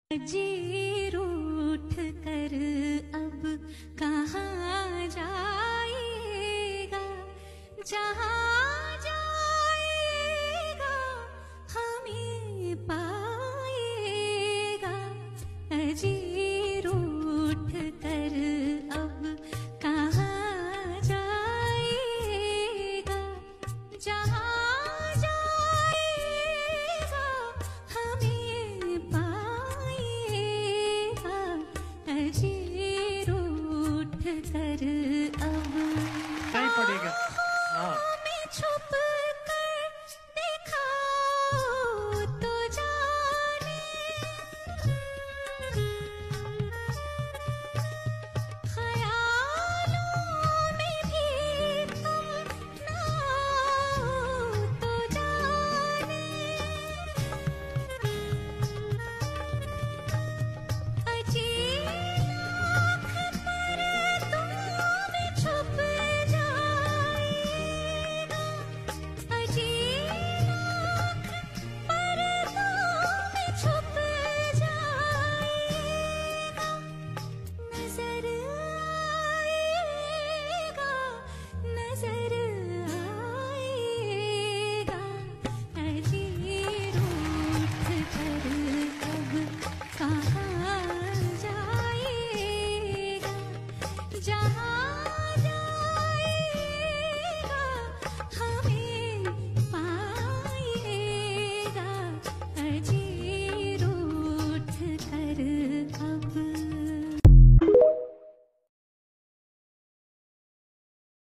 Melodious Voice, and suddenly i sound effects free download